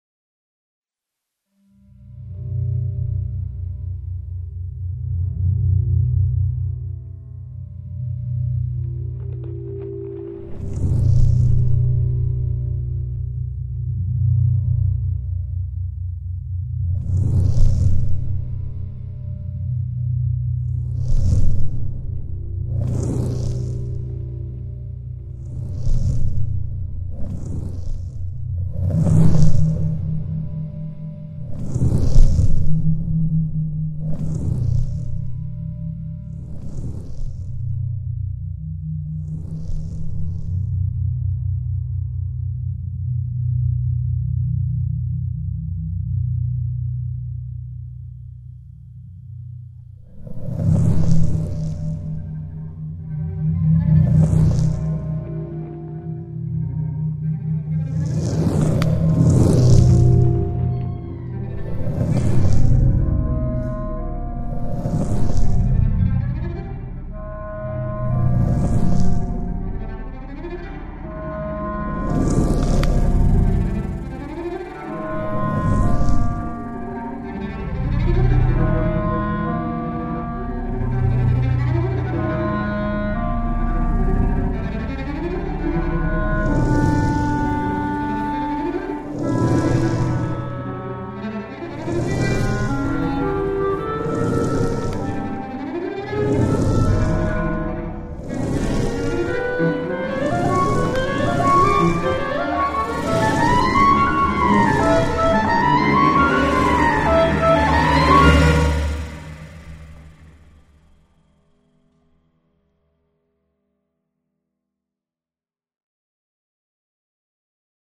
2010, pour clarinette, violoncelle, percussions, deux chanteurs et sons fixés.